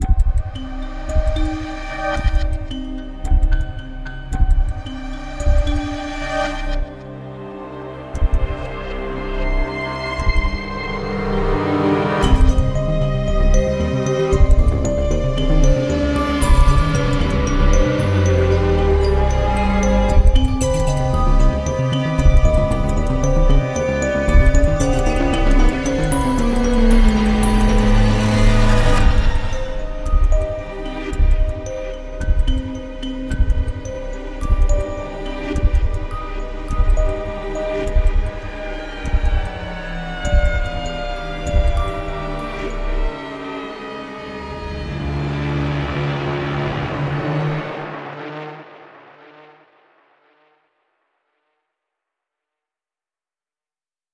弹拨源具有独特的能力，可以为场景注入即时紧张和剧烈的情绪变化。
Heavyocity 的 Mosaic Pluck 模拟合成器 VST 插件具有一系列弹拨源（包括合成器、弦乐、竖琴、加工过的编钟和拇指钢琴），甚至可以切割最密集的乐谱混音。
有了这个 Heavyocity Mosaic Pluck VST 插件，将模拟合成器与采样的有机乐器和攻击层分层，以构建独特的混合纹理。